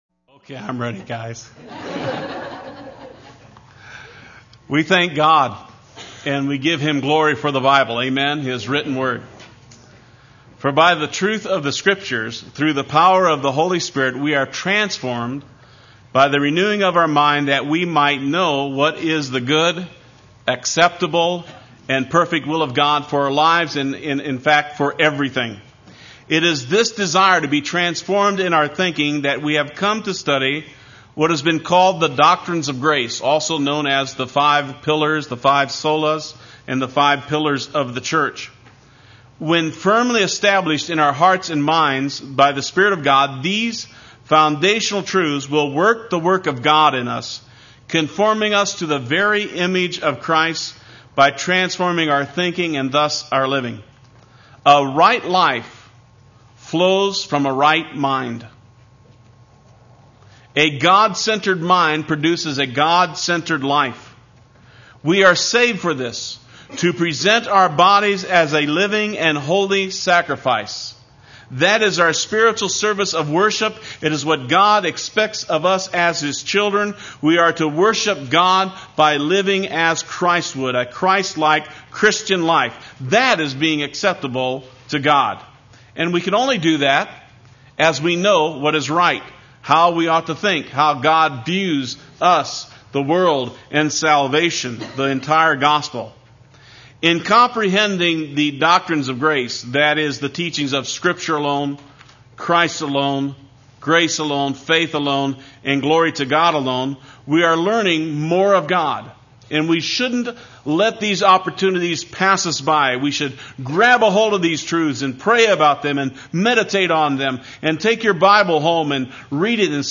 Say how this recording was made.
Justification by Faith Alone Part I Sunday Worship